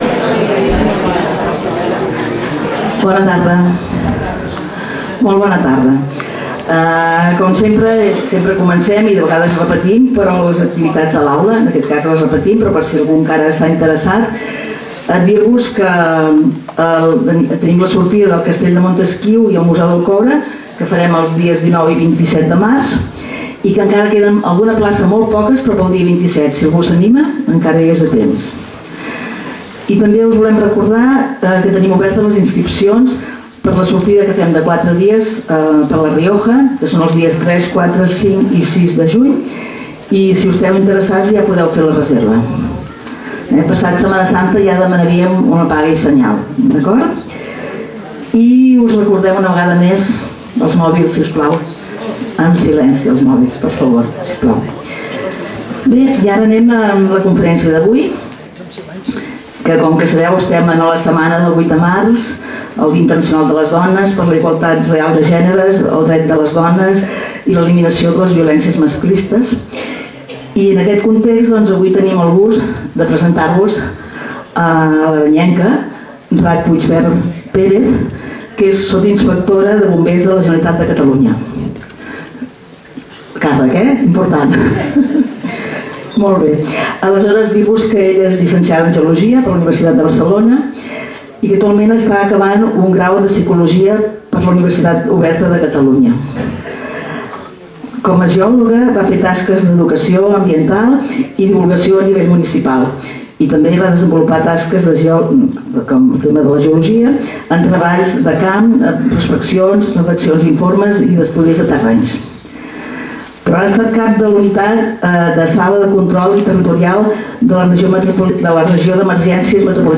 Lloc: Casal de Joventut Seràfica
Categoria: Conferències